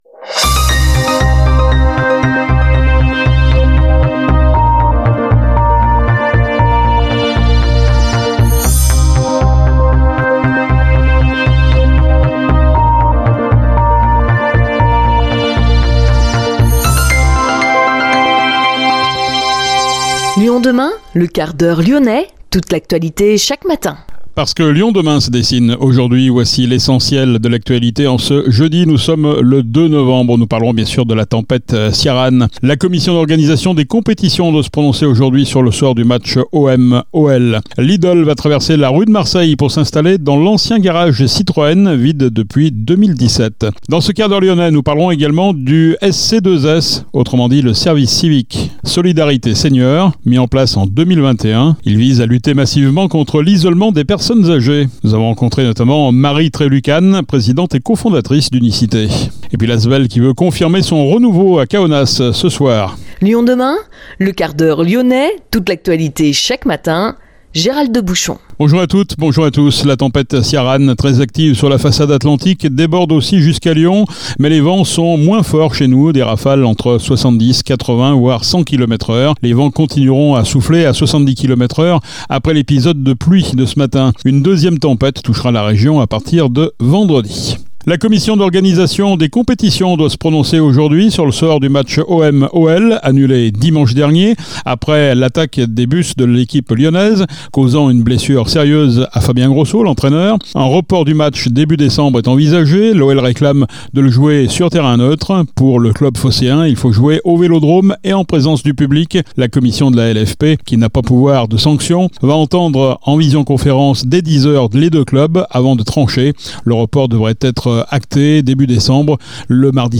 Reportage à l’ EHPAD Monplaisir La Plaine , dans le 8ème arrondissement de Lyon.